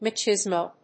音節ma・chis・mo 発音記号・読み方
/mɑːtʃíːzmoʊ(米国英語), mətʃízməʊ(英国英語)/